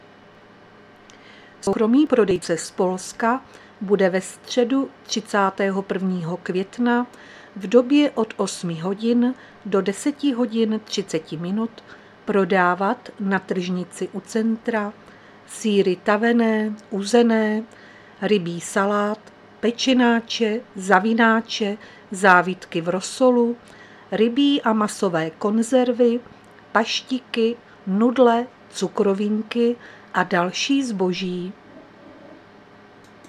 Záznam hlášení místního rozhlasu 30.5.2023